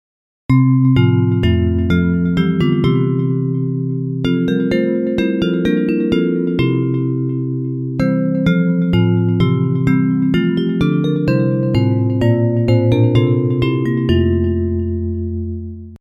Bells Version (.mp3)